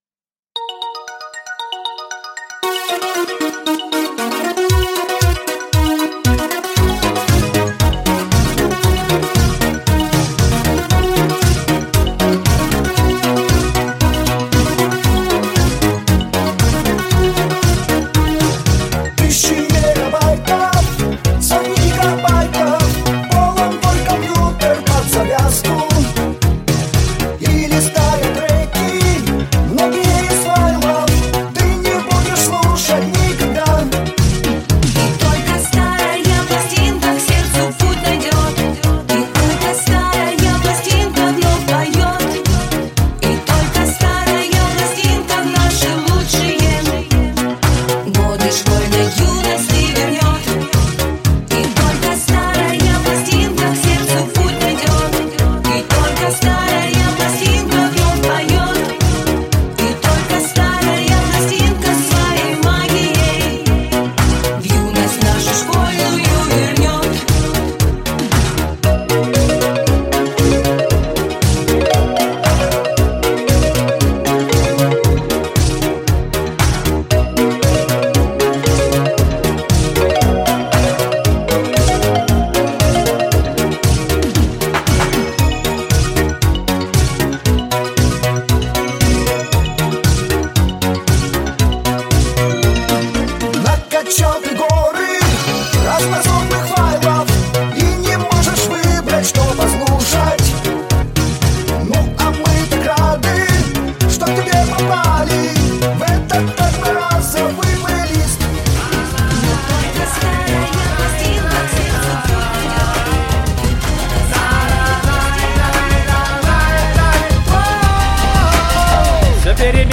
Disco-Pop-Dance Fractal